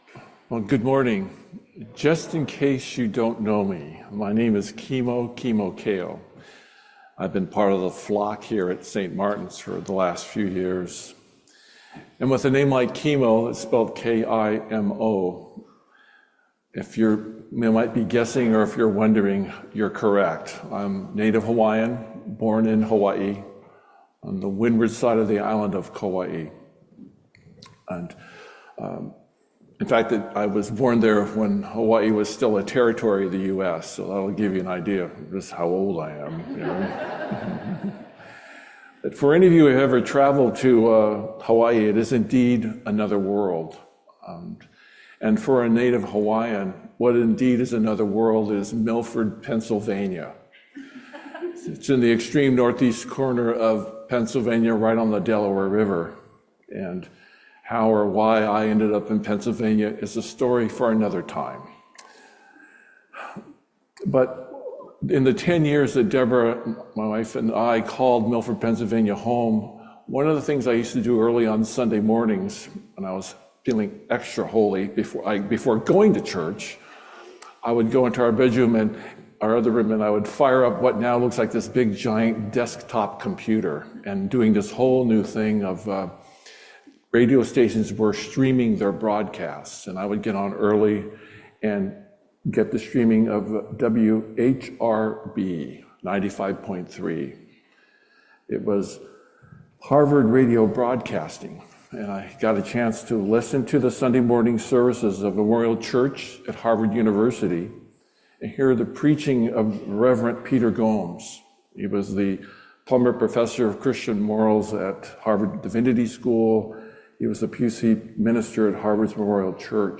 "The Holy Trinity": Sermon